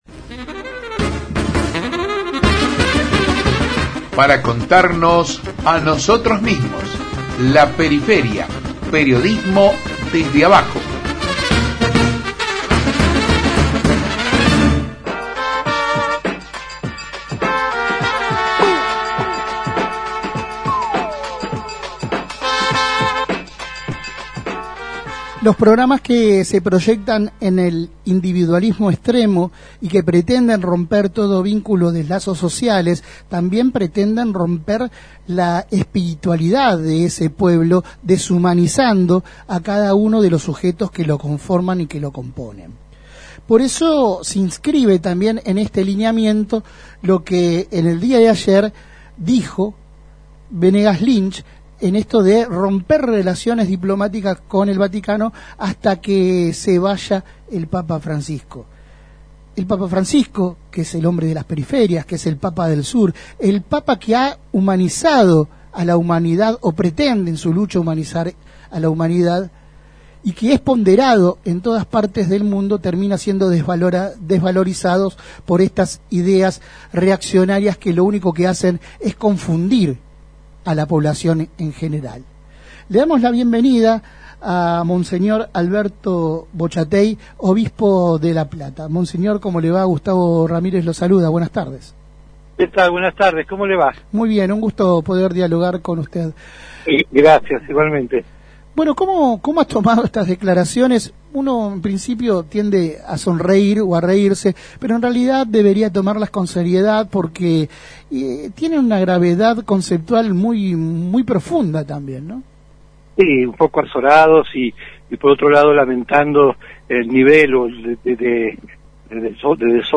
En La Periferia dialogamos con Monseñor Alberto Bochatey, Obispo de La Plata, sobre los dichos vertidos por Alberto Benegas Linch durante el acto de cierre de campaña de La Libertad Avanza. Allí el representante libertario pidió romper relaciones con el Vaticano.
Compartimos la entrevista completa: